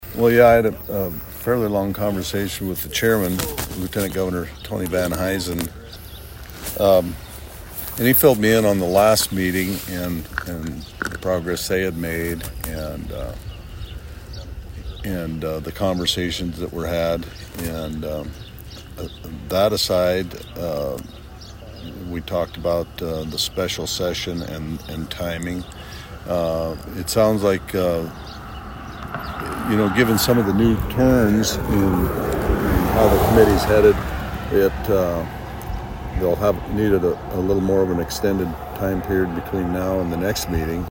Govenor Rhoden took time to speak with KSDN and update on other items starting with the Project Prison Reset Task Force.